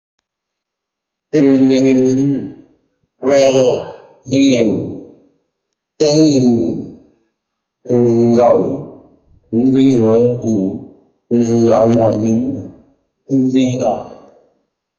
Obese, heavy woman breathe heavily 0:47 Created Nov 8, 2024 5:16 PM Buatkan saya efek suara orang ngos ngosan 0:15 Created Apr 20, 2025 3:54 PM Obese, heavy woman breathe slow heavily 0:47 Created Nov 8, 2024 5:18 PM